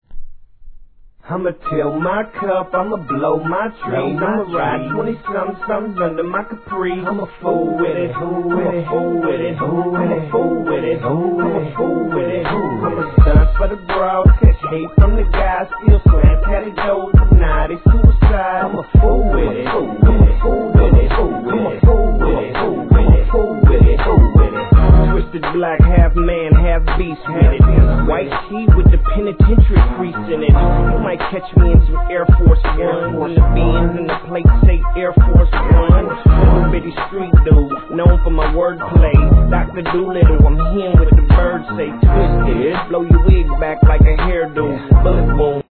G-RAP/WEST COAST/SOUTH
笛の上音がこのSOUTHビートには何とも新鮮に感じられて最高です。